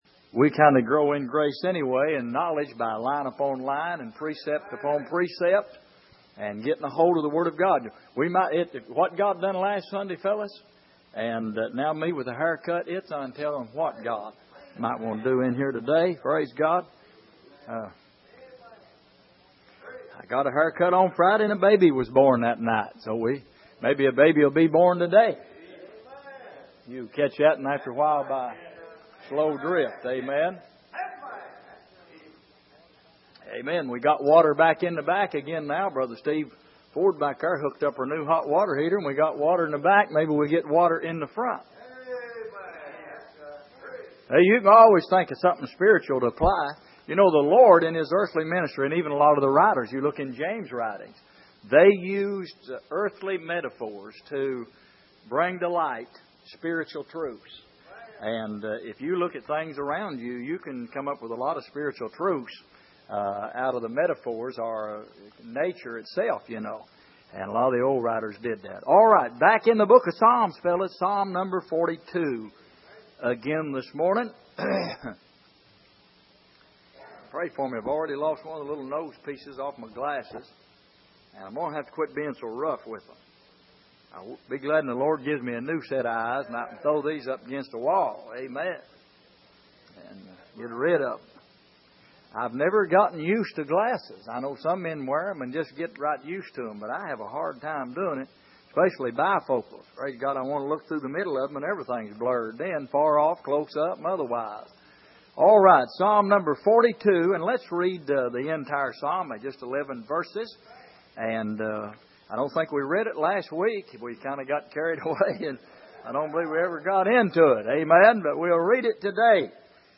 Passage: Psalm 42:1-11 Service: Sunday Evening